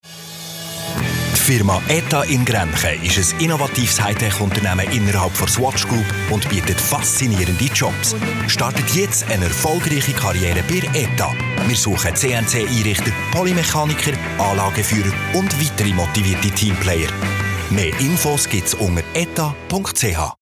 Werbung Schweizerdeutsch (BE)
Sprecher mit breitem Einsatzspektrum.